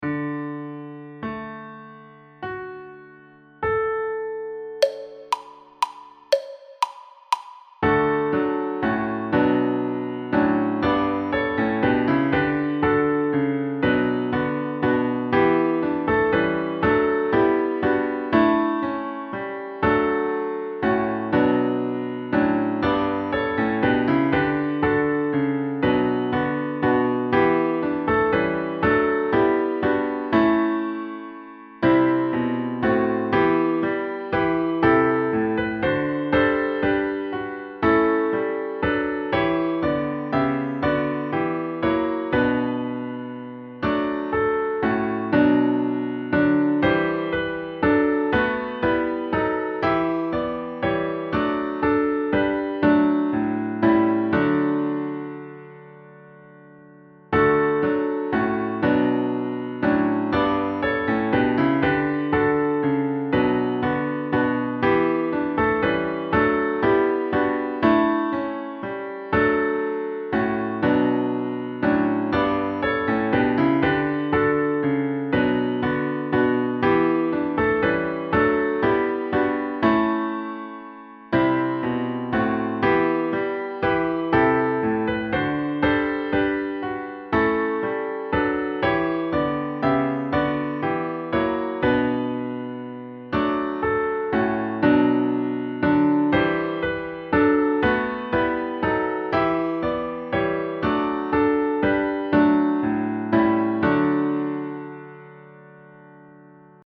You’ll sing Verse 1: melody and Verse 2: alto, tenor, or bass line (sopranos and altos will all sing the alto line).